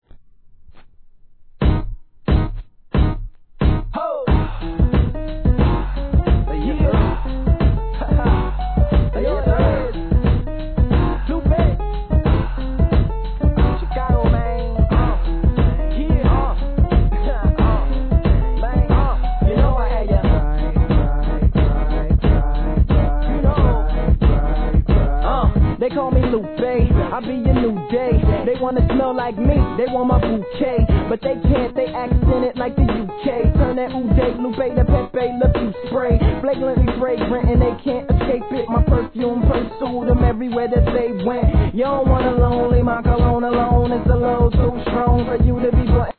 HIP HOP/R&B
ピアノの上音が思わず踊りたくなってしまいます。